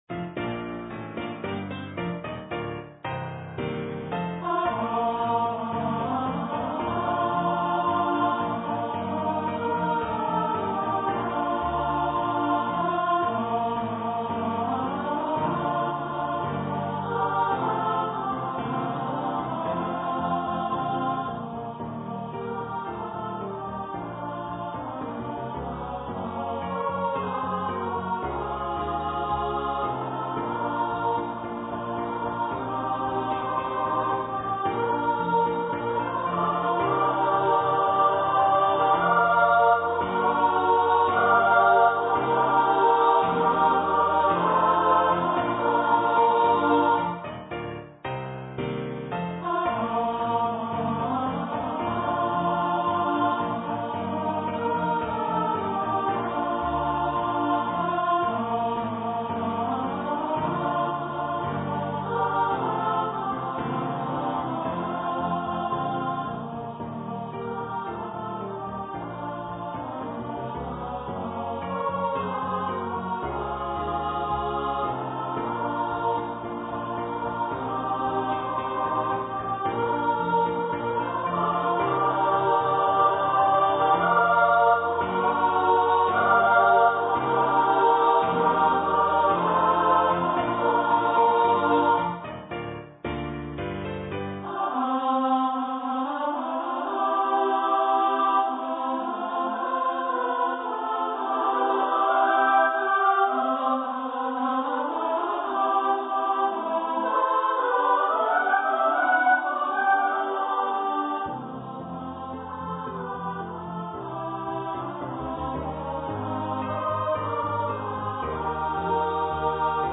for SSA choir
Seven Carols for Christmas for choir and orchestra or piano
(Choir - 3 part upper voices)